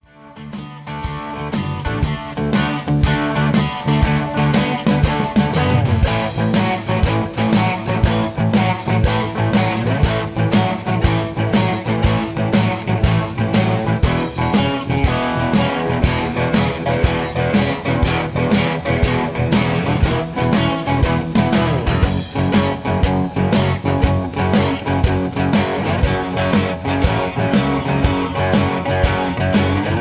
Quality Jam Tracks. These are real Tracks not midi files.